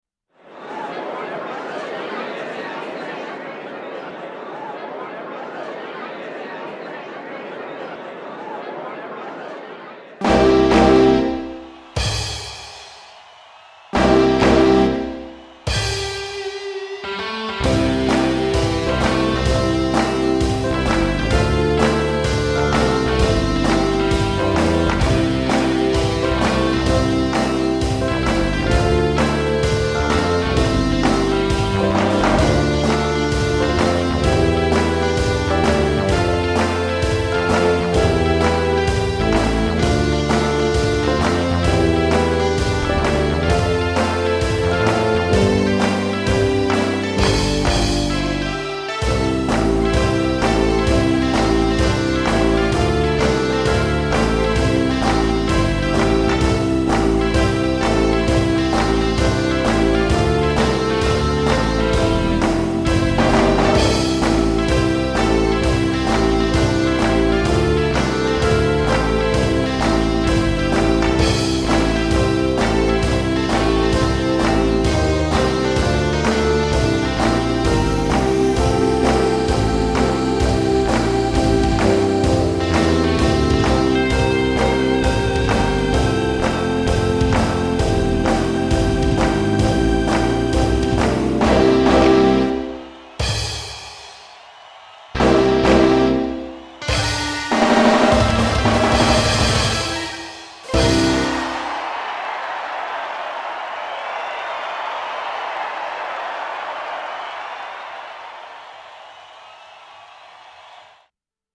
ギター系の楽器はヌンチャクを揺らすとビブラートが掛かるので、それをちょっとだけ活用しました。